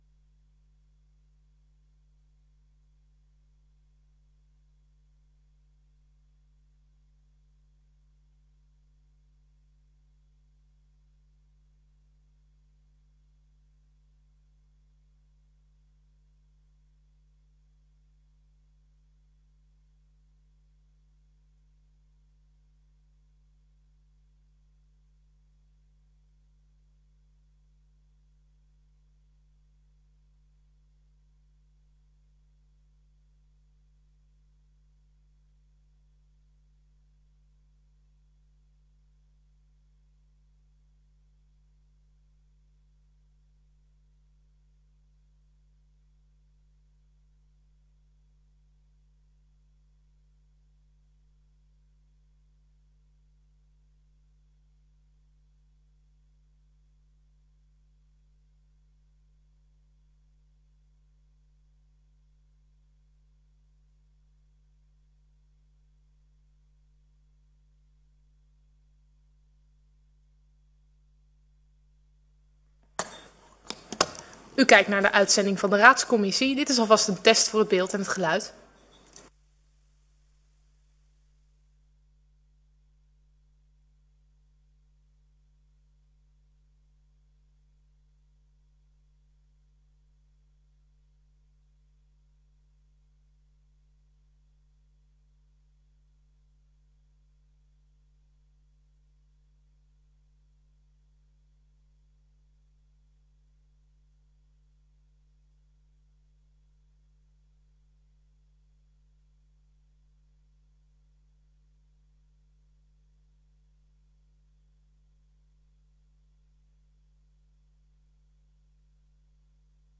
Raadscommissie 20 januari 2025 19:30:00, Gemeente Dalfsen
Locatie: Raadzaal